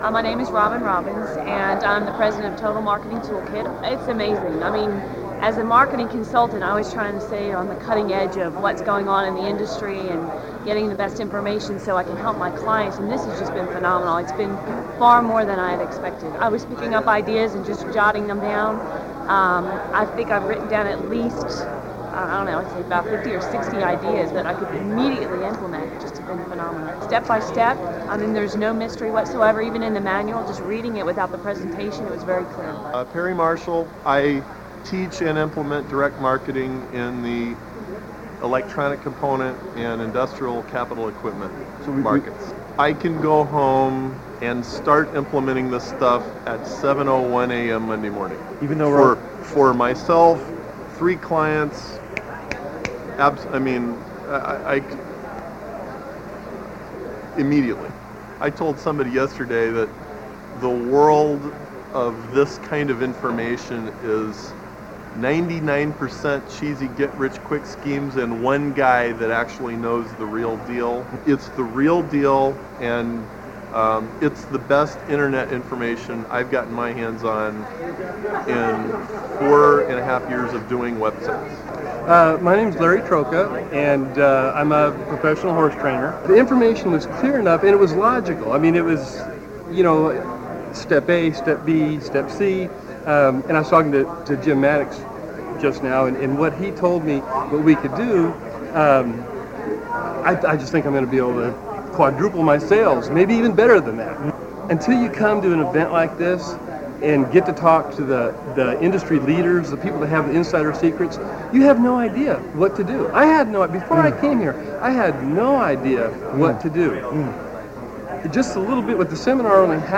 Live, on-the-spot comments from some of the people who attended the first System Seminar when I opened it to the public.
2002testimonials.mp3